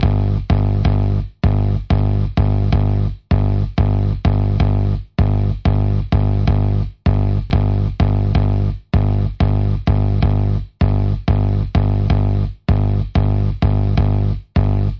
这里有一个简单的轨道，关于这些人类中，一个人碰巧将手指移过他原始箭头弓的弦。
标签： 实验性 独特 曲目 电子音乐
声道立体声